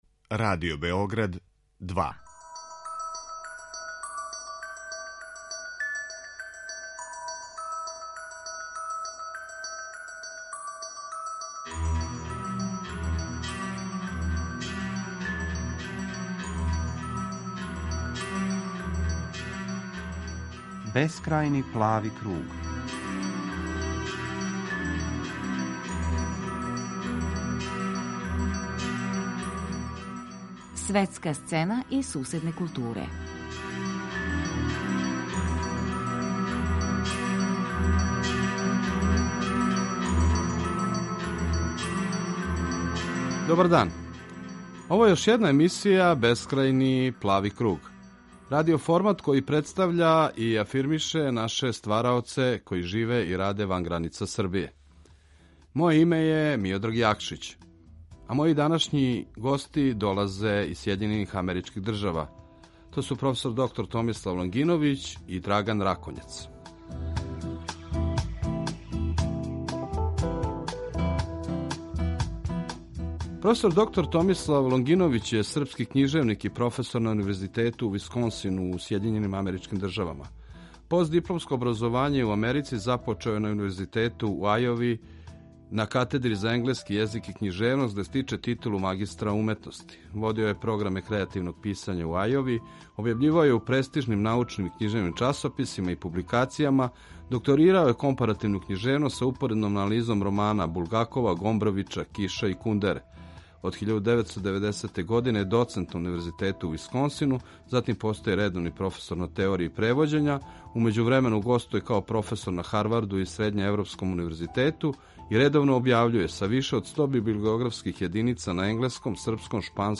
Извор: Радио Београд 2